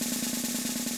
Snares
SNROLL.WAV